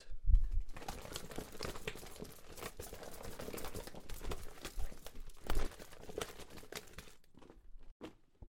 Shaking_rubber_mask_hard_1
OWI rubber shake sound effect free sound royalty free Memes